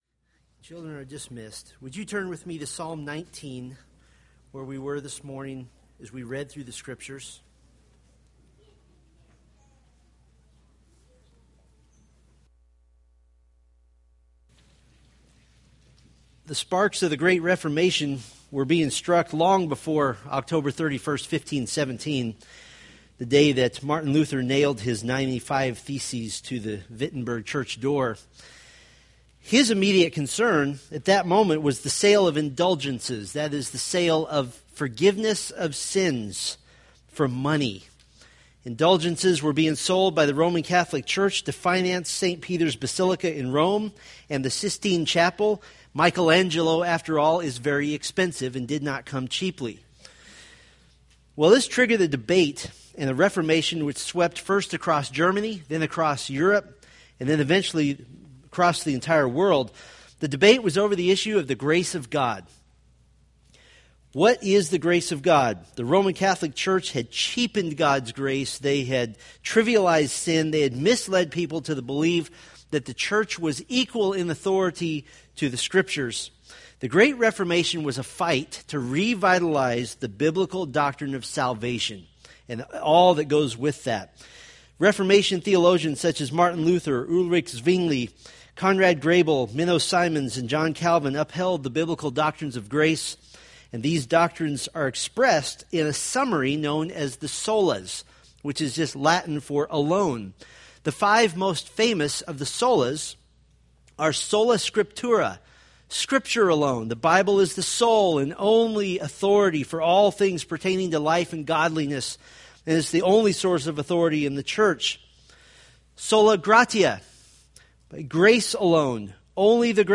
Psalms Sermon Series